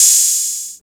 TR808OH.wav